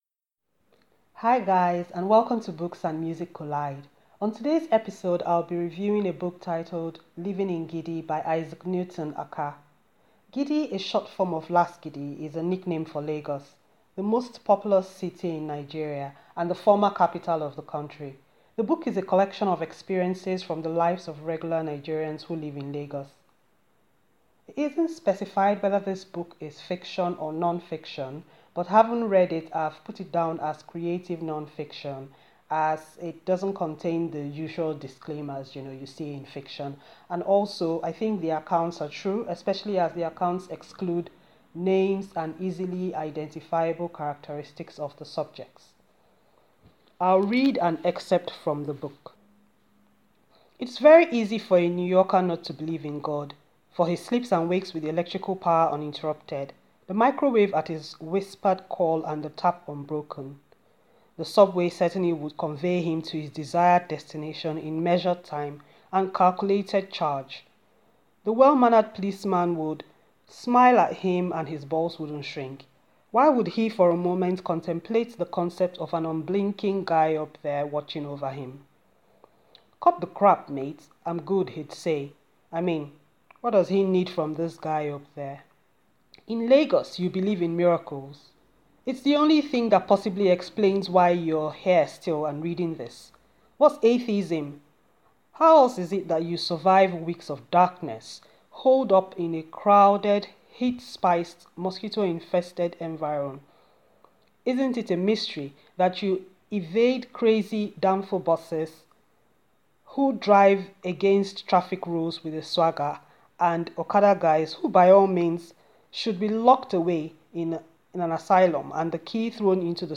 An audio book review of Living in Gidi by Isaac Newton Akah.